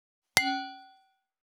318,ガラスのグラス,ウイスキー,コップ,食器,テーブル,チーン,カラン,キン,コーン,チリリン,カチン,チャリーン,クラン,カチャン,クリン,シャリン,チキン,コチン,カチコチ,チリチリ,シャキン,
コップ